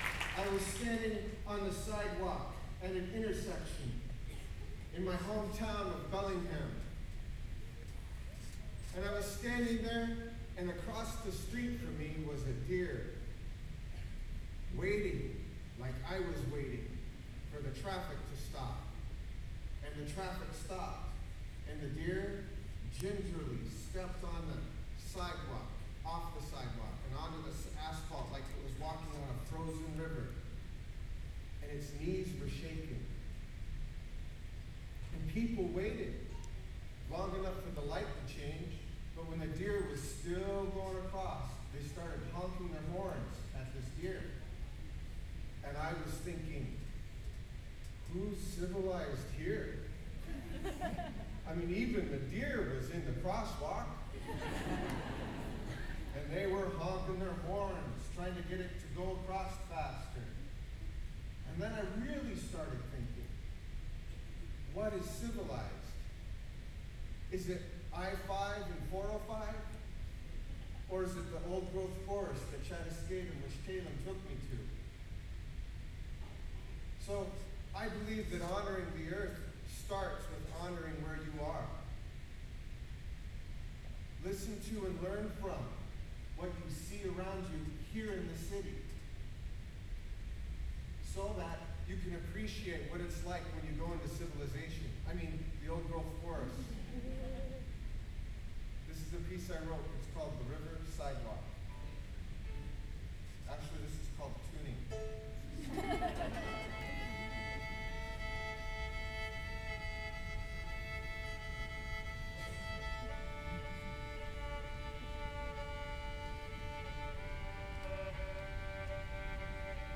lifeblood: bootlegs: 2003-04-15: hub ballroom (university of washington) - seattle, washington (honor the earth benefit with winona laduke)